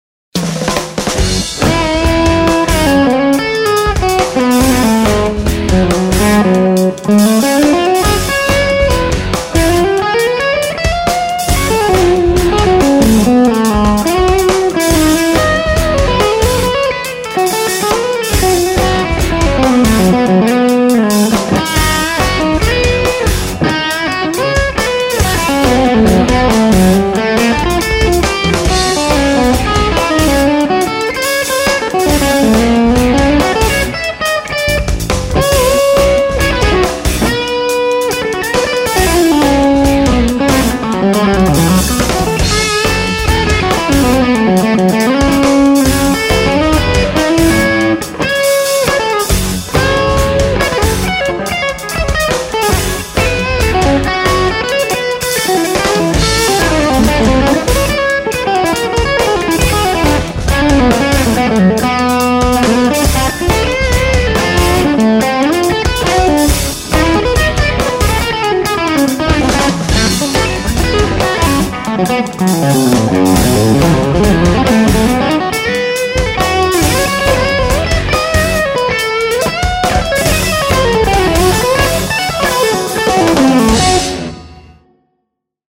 I added a whole bunch of little ceramics to my loop to simulate the capacitive losses of the cable.
SM57 and Baker B1 on bridge, reasonable volume.
Yours has a more smooth attack.
For me, this tone is a little too mushy, though I do really like it when you dig in and pick a little harder.
That makes is mushier and a little less articulate on top.
Still punchy and articulate, but smoooooth!
The new mix is just teh amp and a little plate verb.